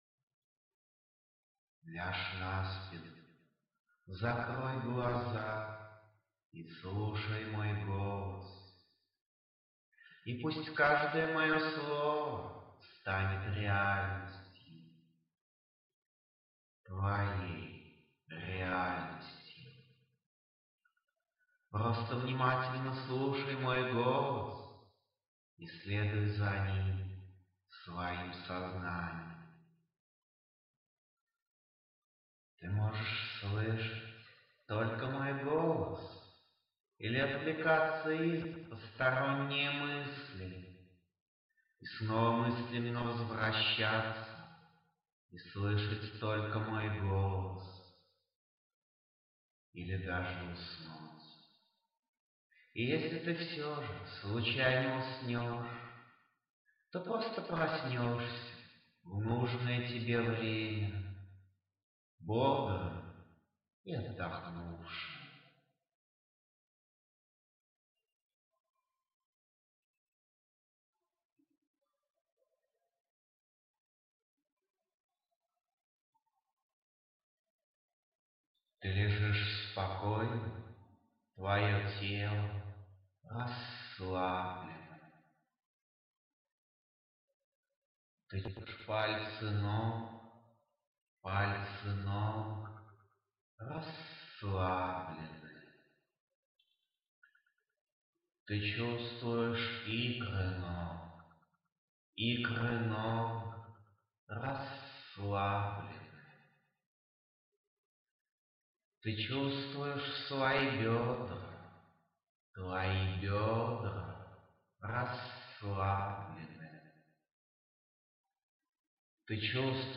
Аудиомедитации
Музыка для медитации